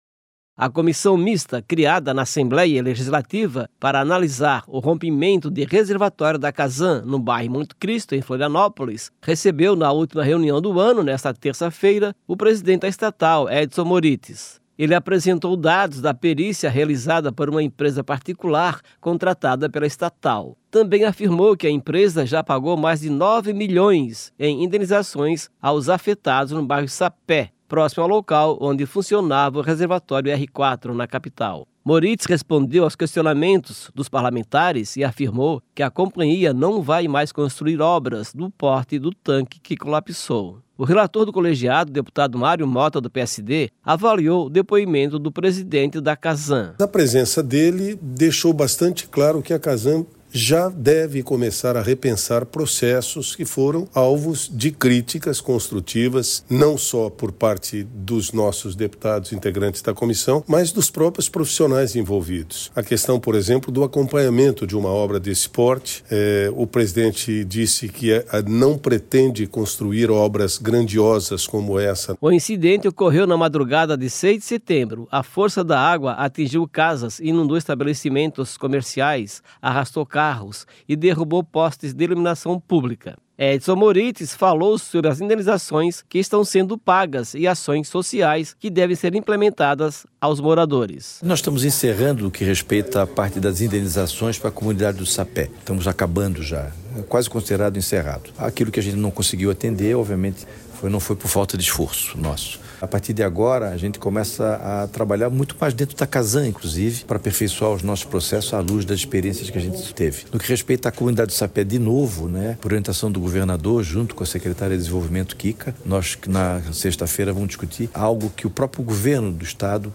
Entrevistas com:
- deputado Mário Motta (PSD), relator;
- Edson Moritz, presidente da Casan.